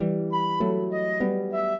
flute-harp
minuet9-3.wav